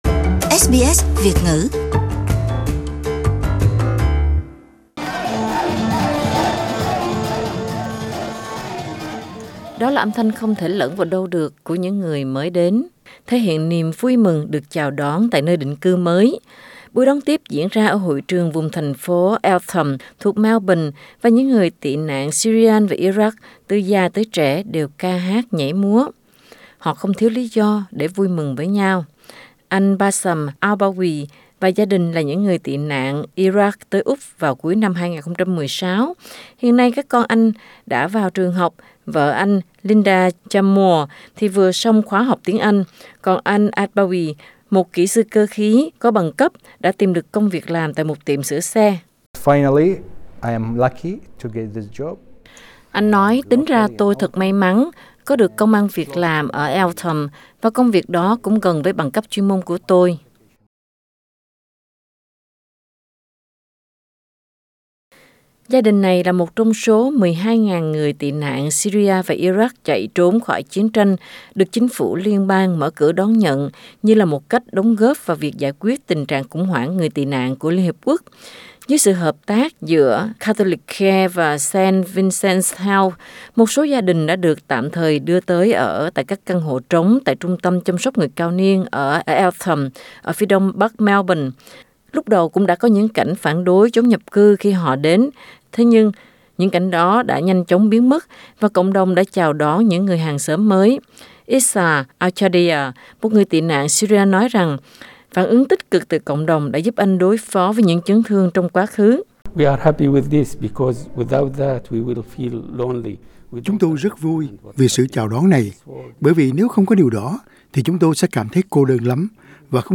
A celebration of the resettlement program in Eltham Source: SBS
Âm thanh không thể lẫn của những người mới đến thể hiện niềm vui mừng khi được chào đón tại buổi đón tiếp diễn ra ở hội trường vùng thành phố Eltham thuộc Melbourne, và những người tị nạn Syria và Iraq từ già tới trẻ đều ca hát nhảy múa.